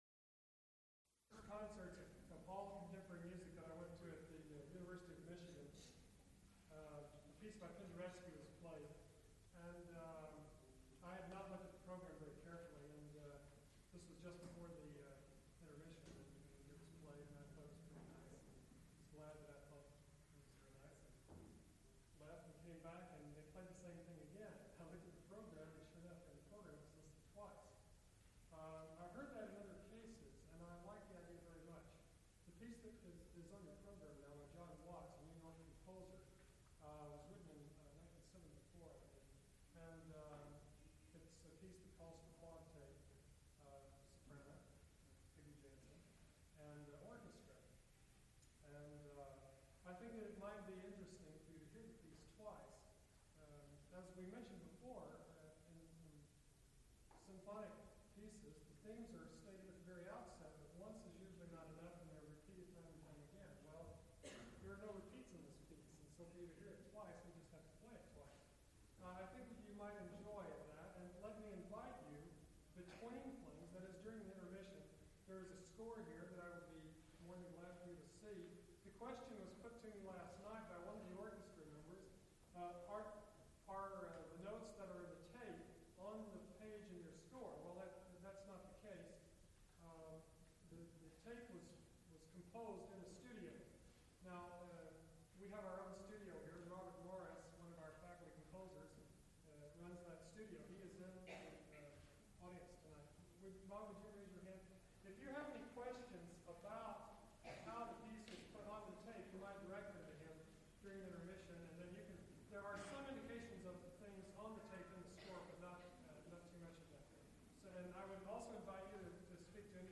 Laugharne : for soprano, tape and orchestra.
Recorded live October 11, 1977, Schenley Hall, University of Pittsburgh.
Suites (Orchestra)
Songs (High voice) with orchestra